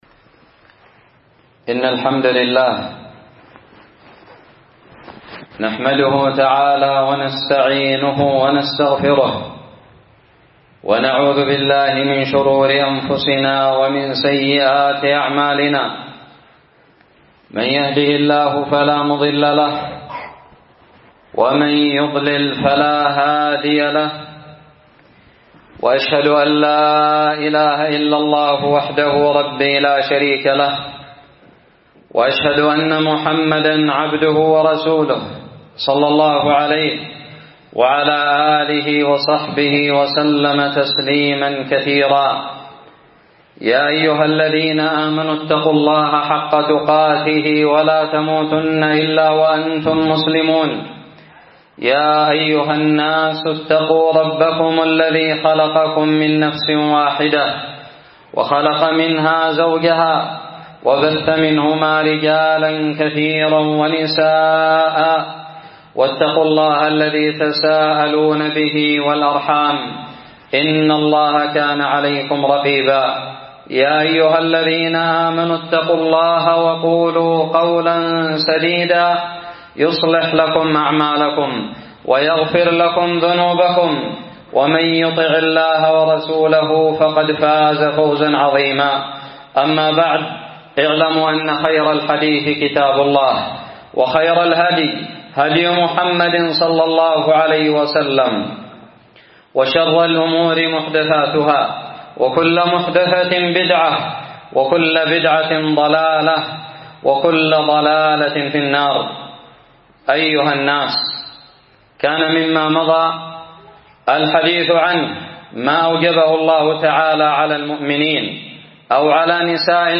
خطب الجمعة
ألقيت بدار الحديث السلفية للعلوم الشرعية بالضالع في 28 صفر 1439هــ